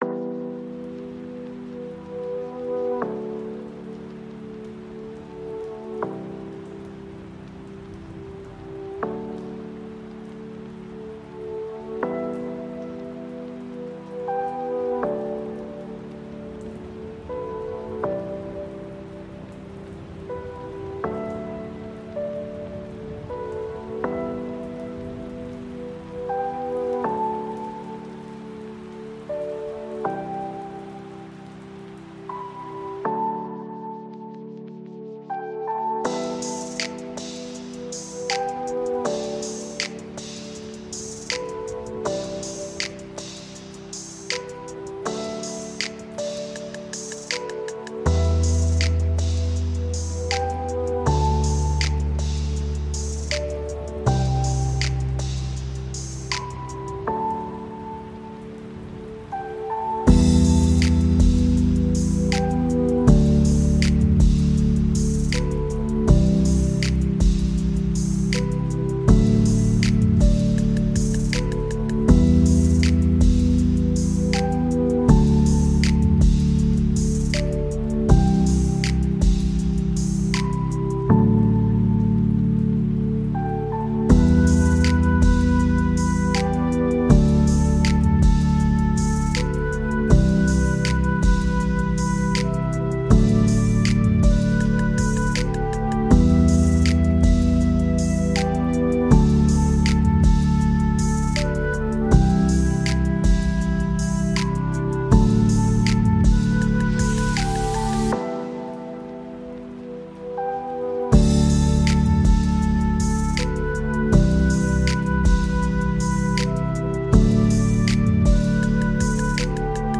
Set To Music, Sorry.